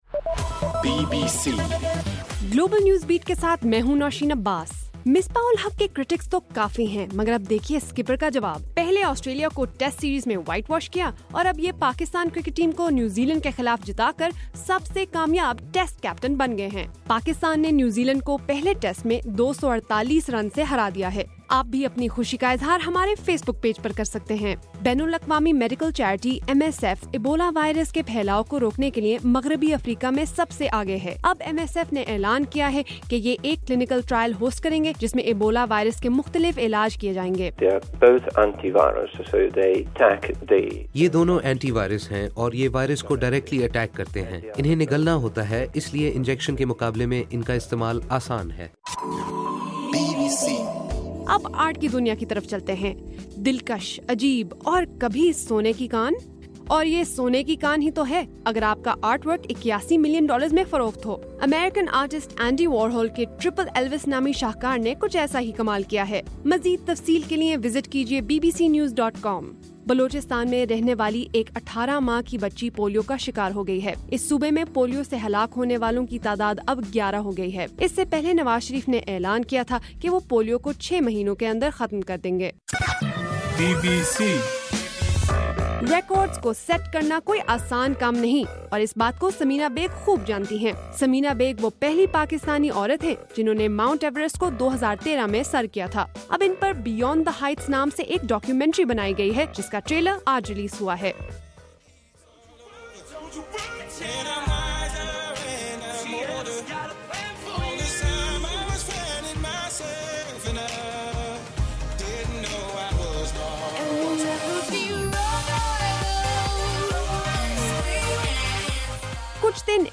نومبر13: رات 8 بجے کا گلوبل نیوز بیٹ بُلیٹن